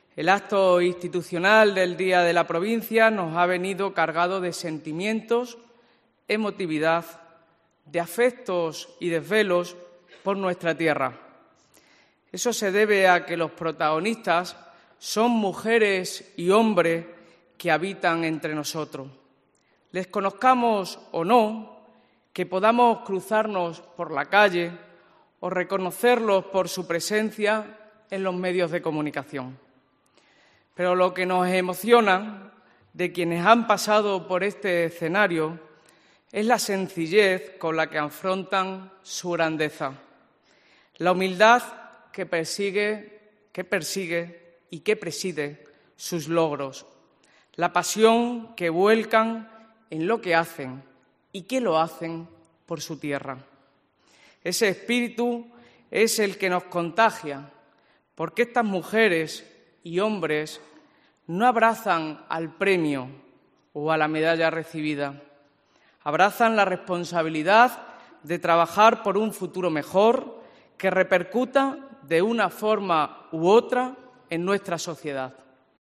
El Teatro Nuevo Calderón de Montijo ha albergado los actos del Día de la Provincia de mano de la Diputación Provincial de Badajoz